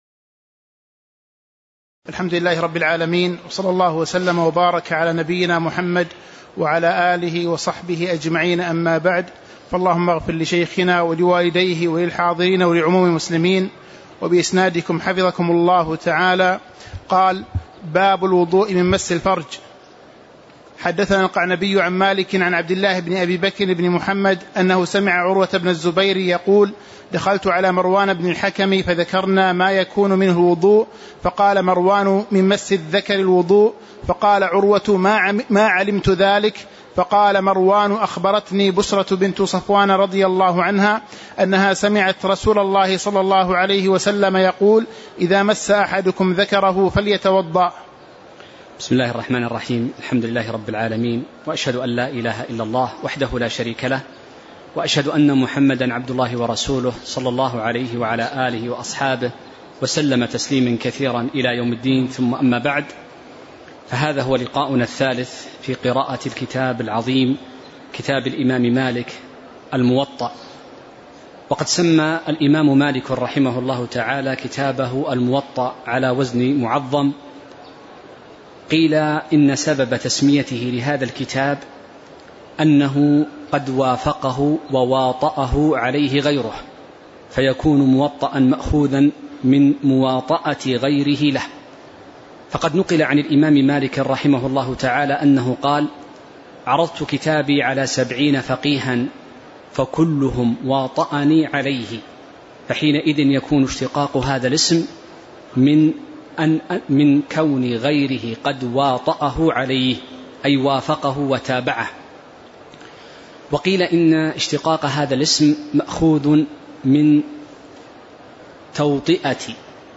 تاريخ النشر ٢٥ جمادى الآخرة ١٤٤٥ هـ المكان: المسجد النبوي الشيخ